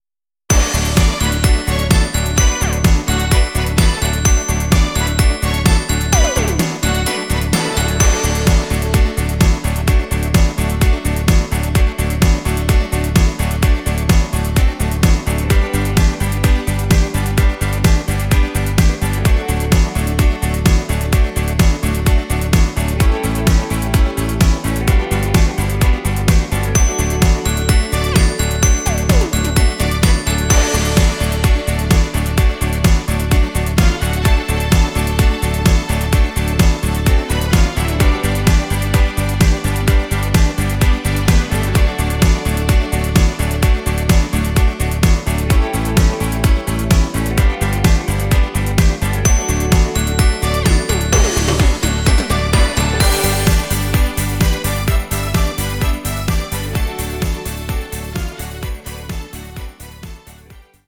new Mix